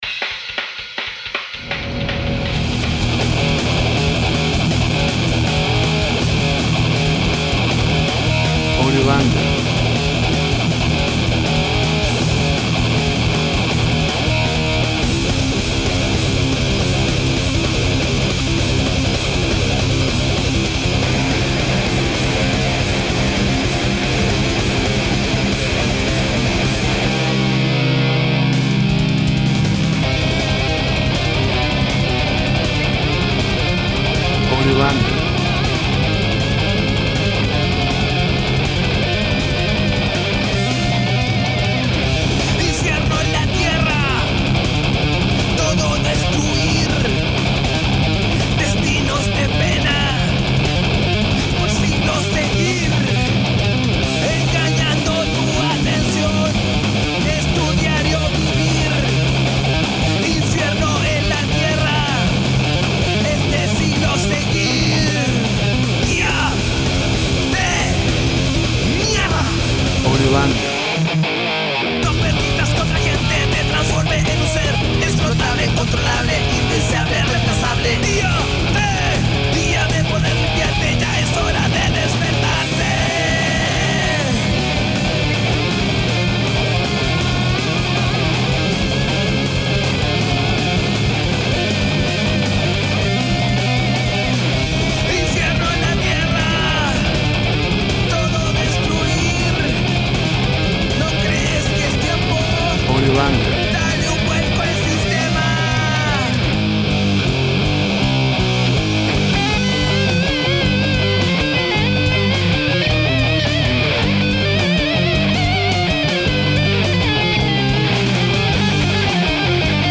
Rock metal fusion track.
Tempo (BPM) 150